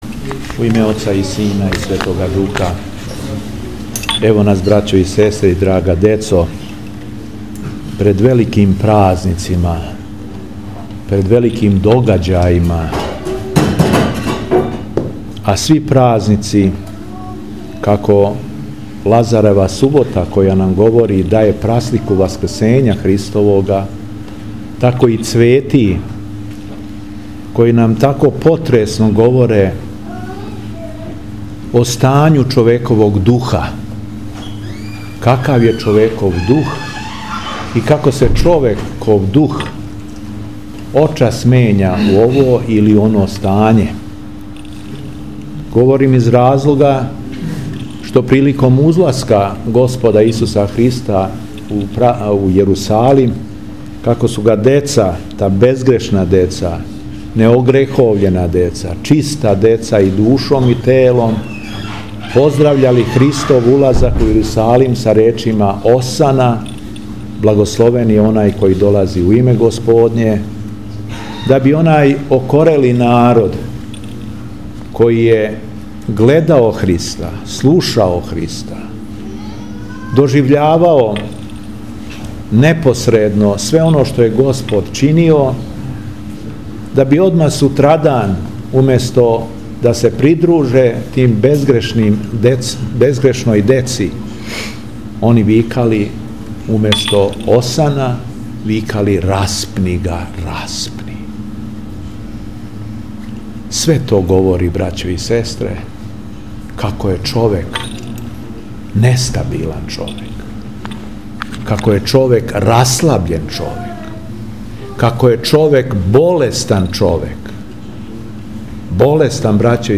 Беседe Епископа шумадијског Г. Јована и Епископа будимског Лукијана